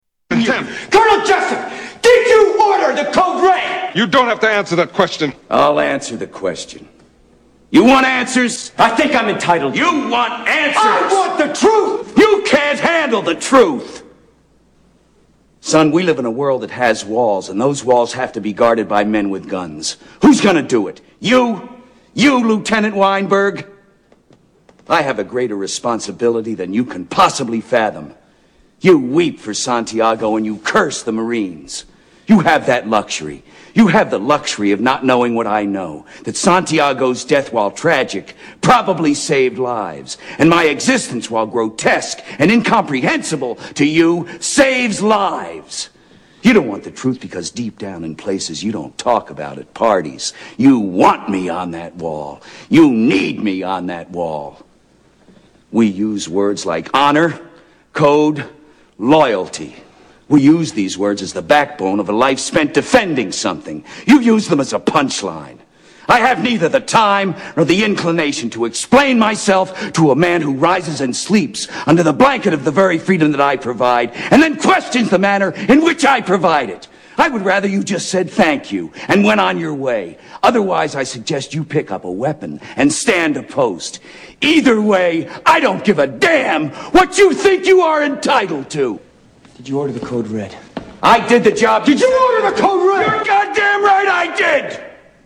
Tags: Greatest Movie Monologues Best Movie Monologues Movie Monologues Monologues Movie Monologue